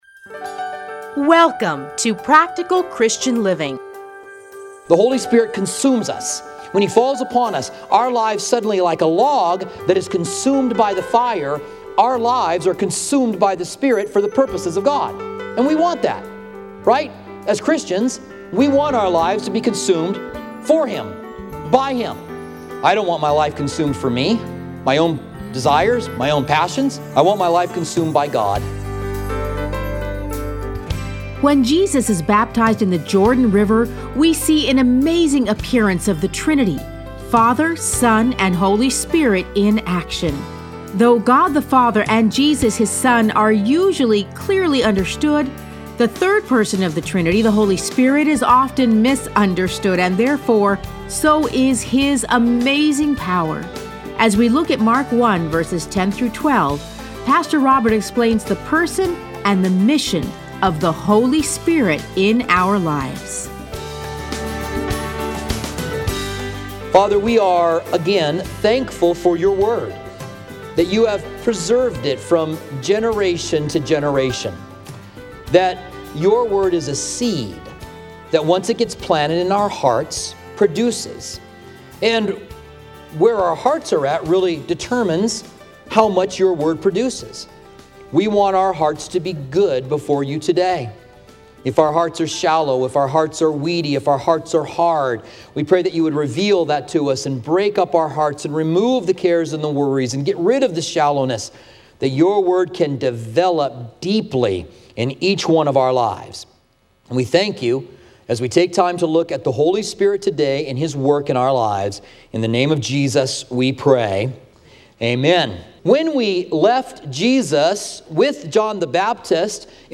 Listen to a teaching from Mark 1:10-12.